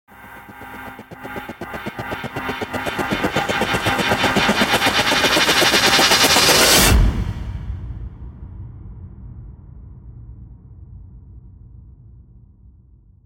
دانلود آهنگ هلیکوپتر 10 از افکت صوتی حمل و نقل
دانلود صدای هلیکوپتر 10 از ساعد نیوز با لینک مستقیم و کیفیت بالا
جلوه های صوتی